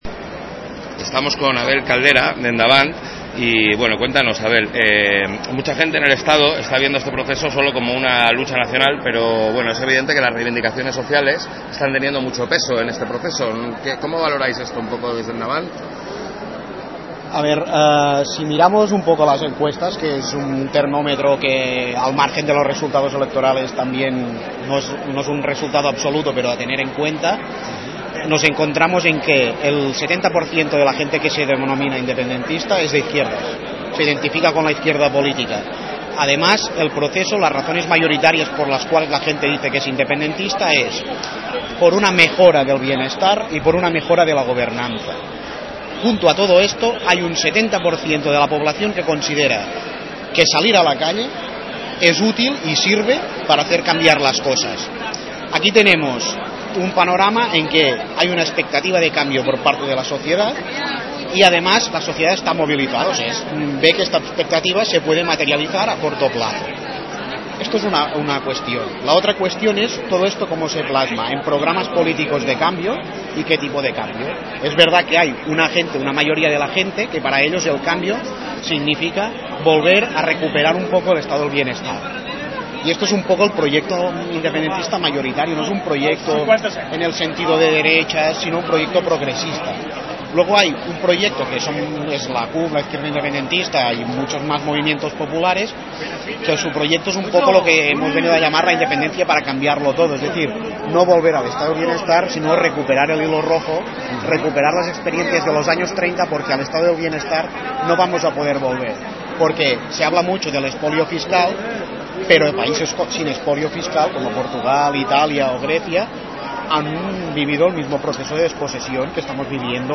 La Haine entrevista